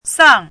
chinese-voice - 汉字语音库
sang4.mp3